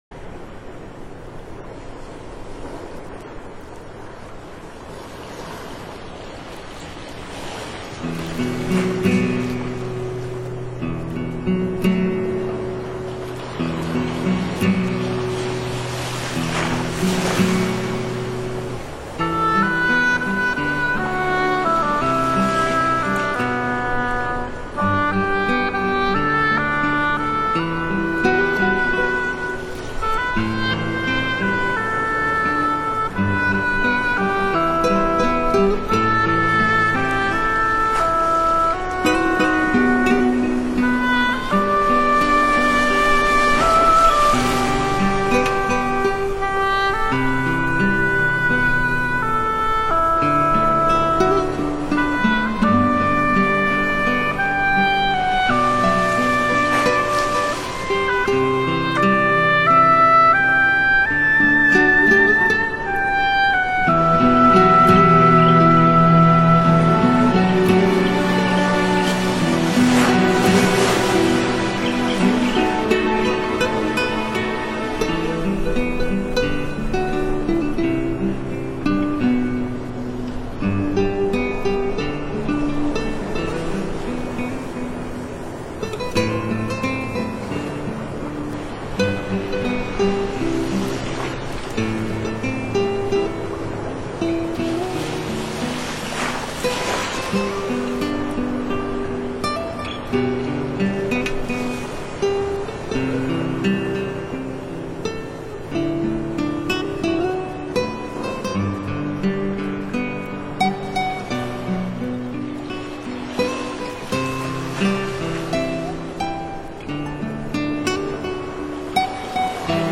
双簧管
大提琴
吉他